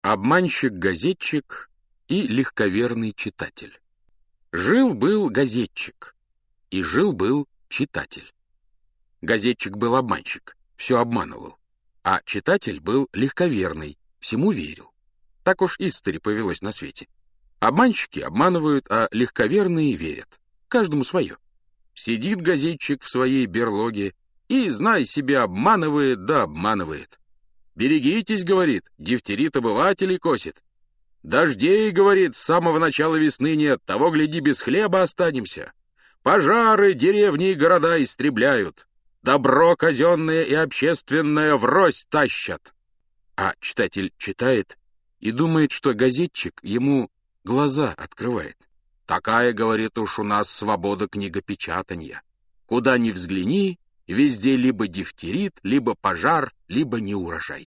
Аудиокнига Сказки - Салтыков-Щедрин Михаил Евграфович - Скачать книгу, слушать онлайн